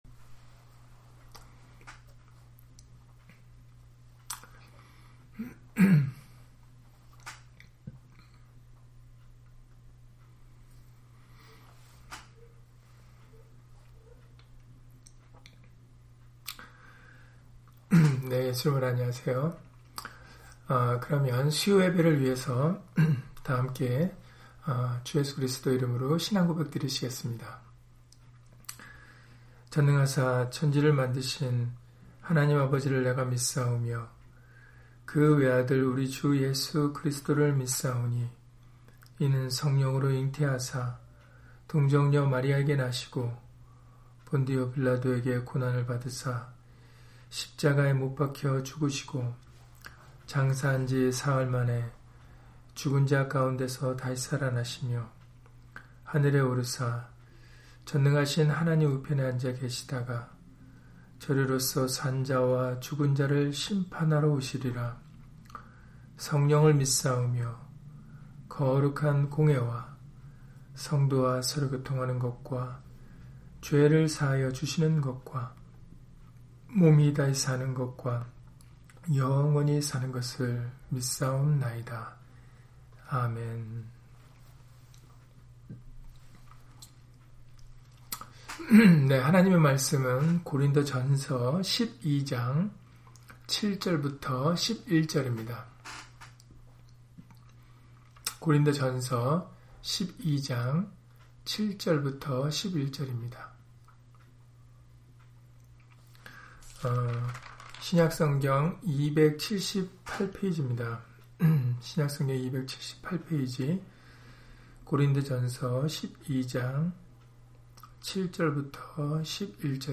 고린도전서 12장 7-11절 [그 뜻대로 각 사람에게 나눠 주시느니라] - 주일/수요예배 설교 - 주 예수 그리스도 이름 예배당